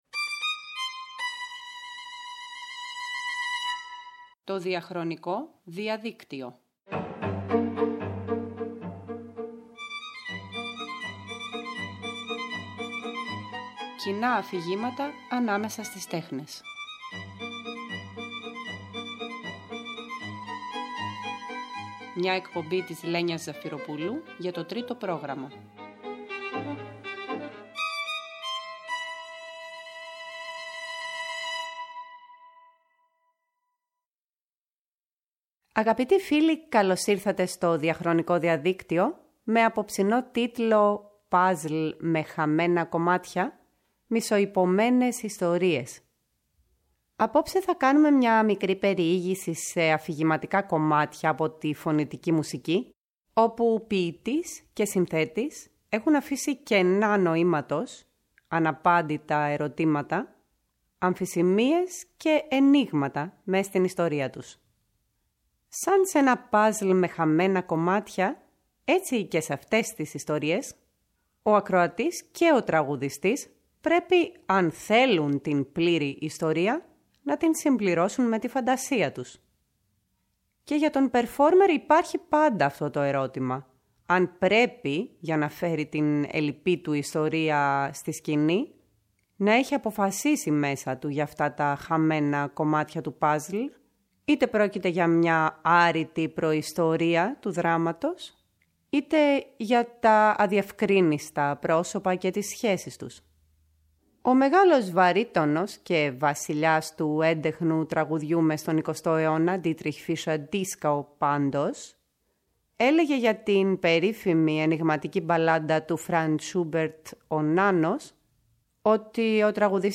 Ένα διμερές αφιέρωμα με ελλειπτικές ιστορίες και γρίφους από τη φωνητική μουσική και την όπερα από το ΔΙΑΧΡΟΝΙΚΟ ΔΙΑΔΙΚΤΥΟ που μεταδίδεται κάθε Κυριακή στις 11 το βράδυ από το Τρίτο Πρόγραμμα.